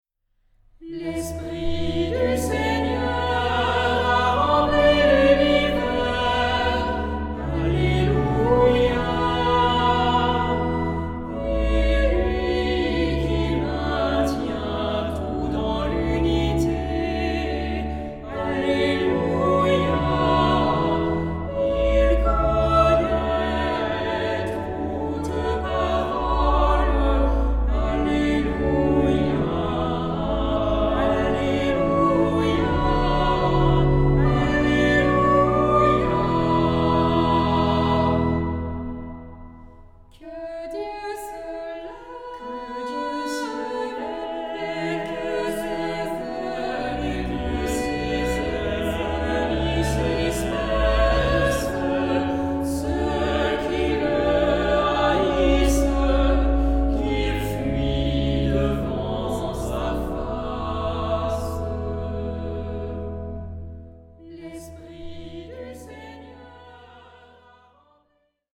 Genre-Style-Forme : Motet ; Sacré
Type de choeur : SATB  (4 voix mixtes )
Instruments : Orgue (1)
Tonalité : mi majeur
interprété par Quatuor vocal